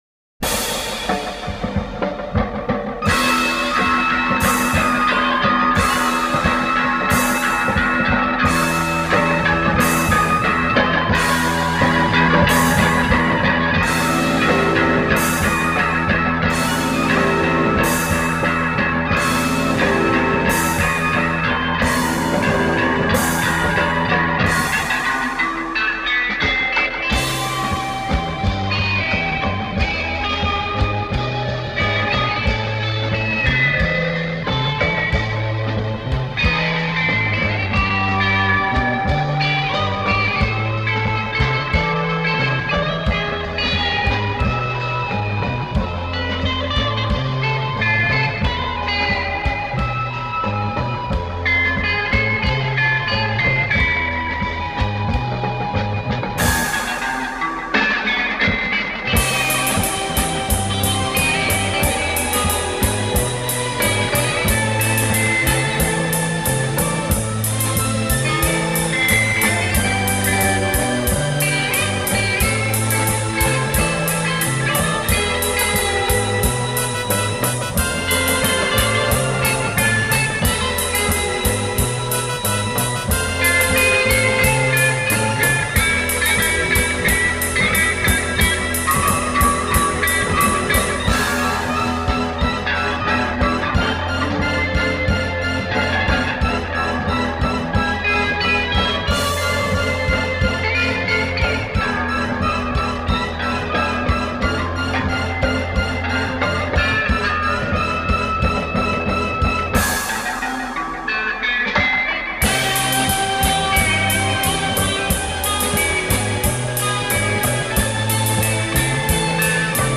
Base creata al computer